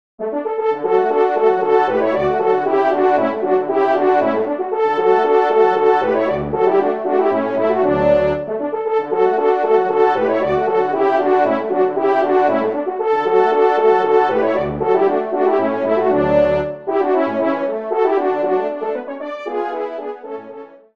24 compositions pour Trio de Cors ou de Trompes de chasse